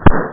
BANG.mp3